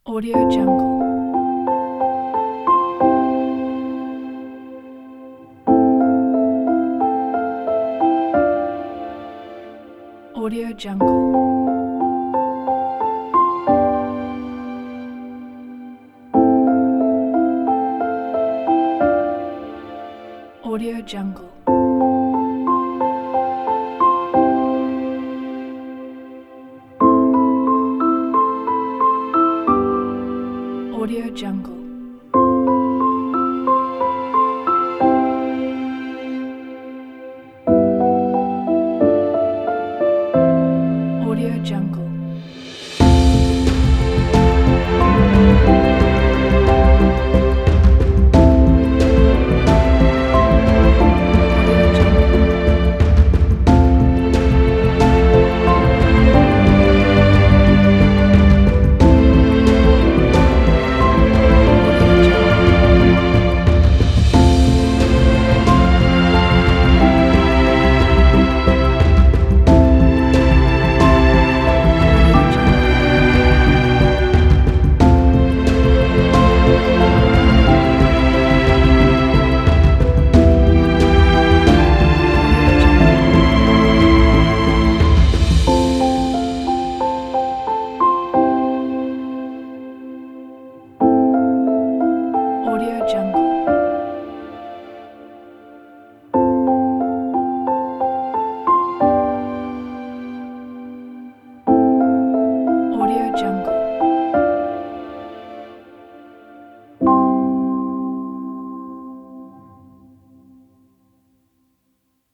سینمایی